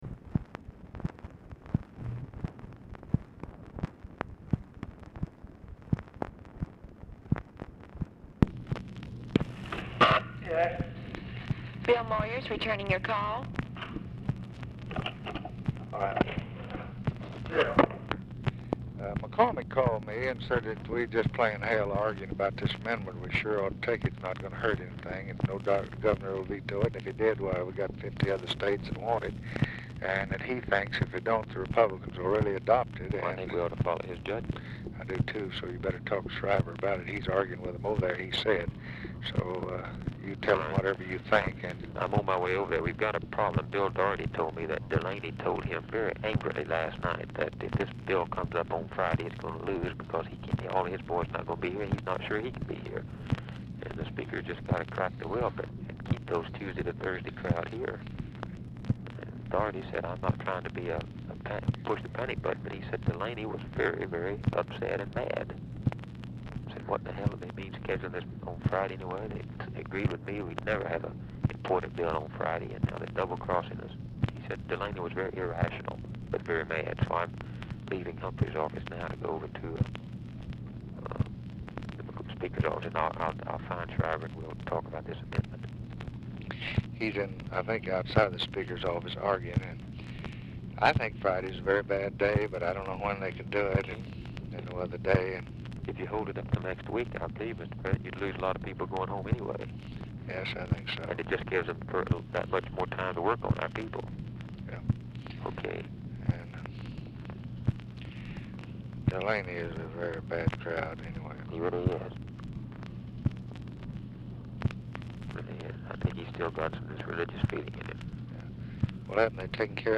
Telephone conversation # 4672, sound recording, LBJ and BILL MOYERS, 8/4/1964, 12:04PM
Format Dictation belt
Location Of Speaker 1 Oval Office or unknown location